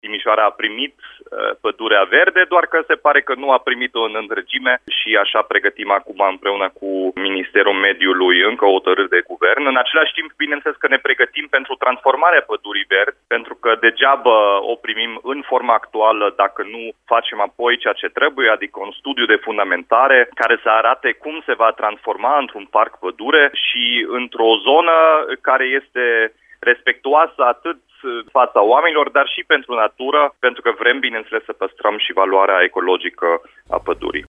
Primarul Dominic Fritz a precizat, la Radio Timișoara, că planurile pentru amenajarea pădurii – parc sunt întârziate întrucât municipalitatea a preluat doar pădurea, fără numeroasele alei și drumuri care o traversează.